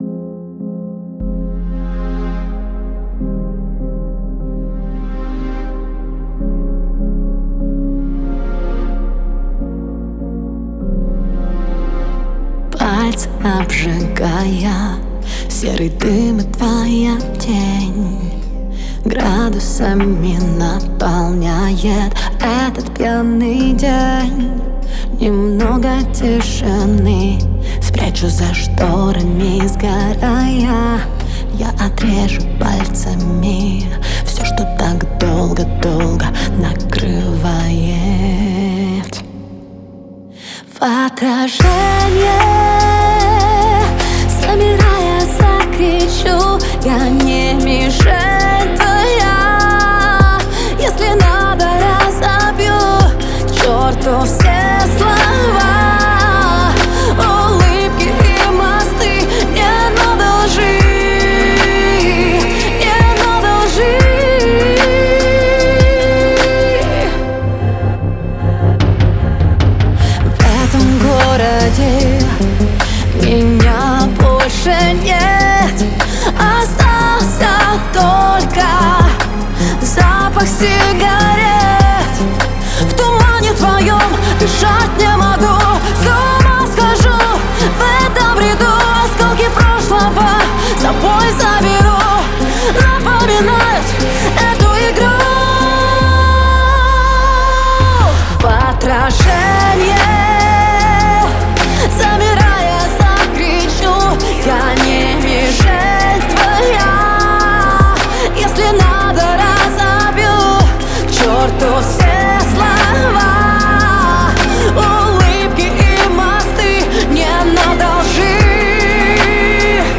это песня в жанре поп